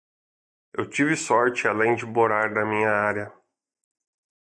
Pronounced as (IPA) /moˈɾa(ʁ)/